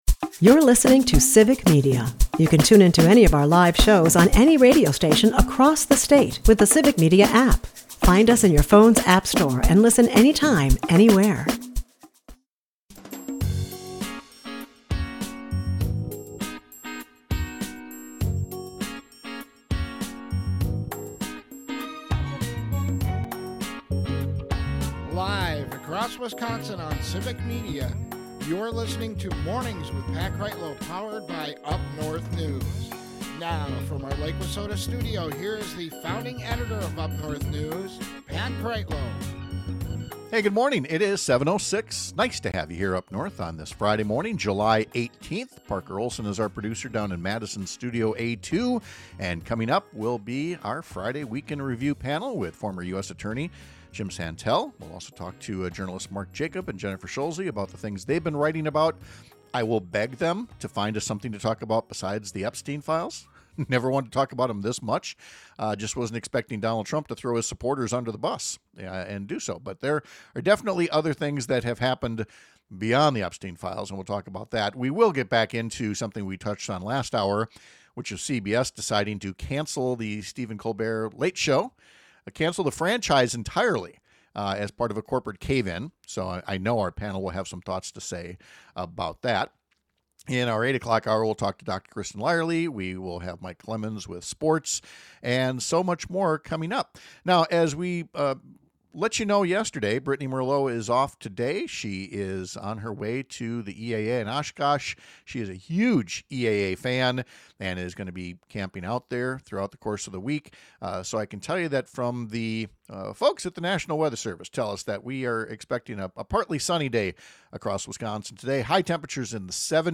We’ll talk to our Week In Review panel about a vote in Congress this week to gut federal funding for public radio and television—a relatively minor line in a budget, but a lifeline to many communities that aren’t otherwise served by for-profit media.